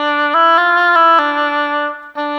Rock-Pop 10 Oboe 01.wav